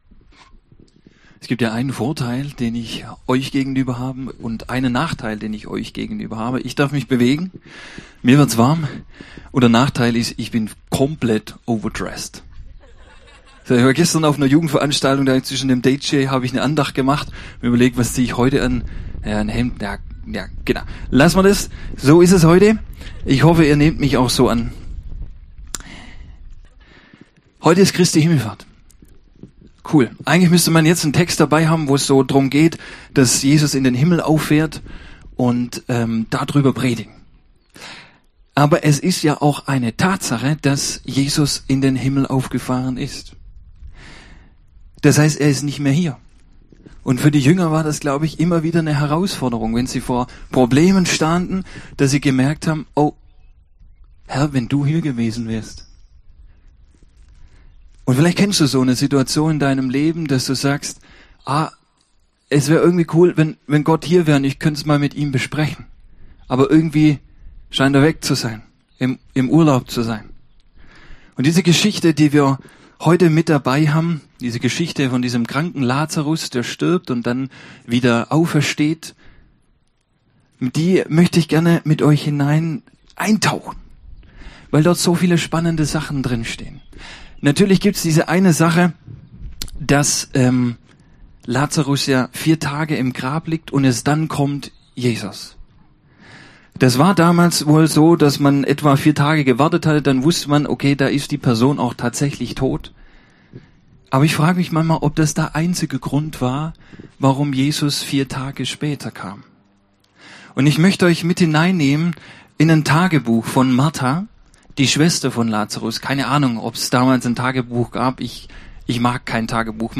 Predigt
im Himmelfahrtsgottesdienst auf der Wiese in Meidelstetten.